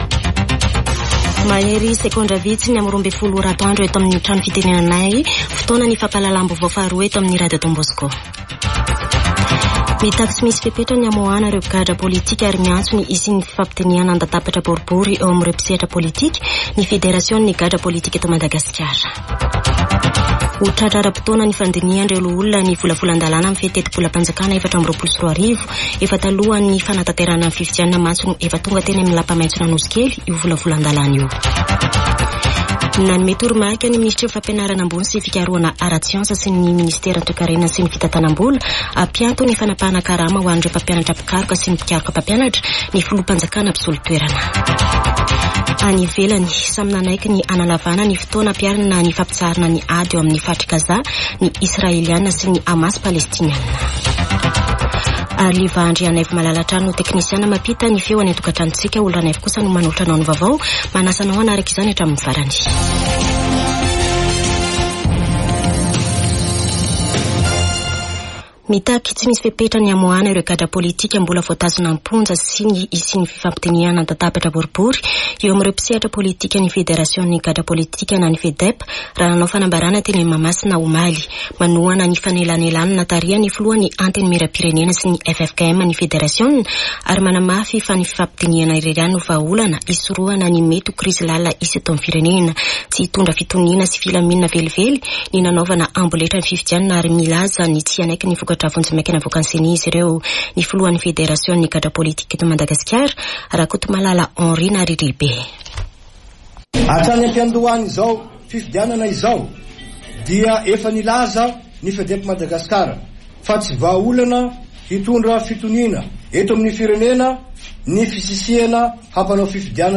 [Vaovao antoandro] Talata 28 nôvambra 2023